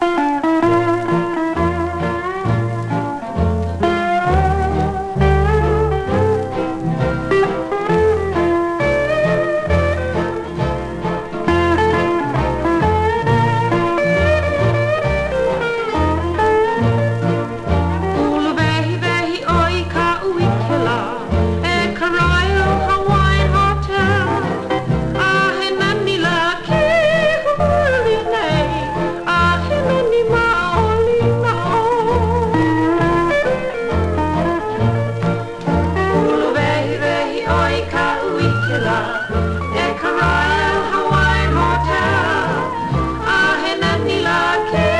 当時のハワイから送られてきた、一瞬でトリップする猛烈な観光地音楽集。
World, Hawaii　USA　12inchレコード　33rpm　Stereo